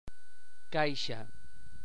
En esta representació espectrogràfica podeu observar l’articulació del mot caixa amb la realització prepalatal de /S/.
Polseu ací i sentireu esta articulació prepalatal:
/kaiSa/